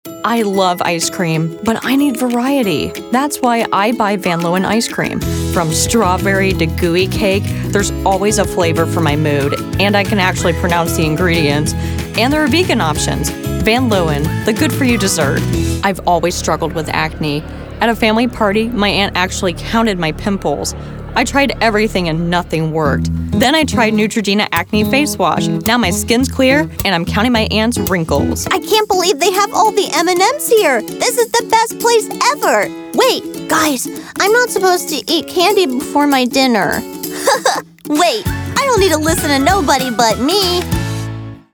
hair: blonde eyes: blue height: 5'2" weight: 110lbs clothing size: 4 shoe size: 7.5 age range: 20 - 30 vocal range: alto/mezzo soprano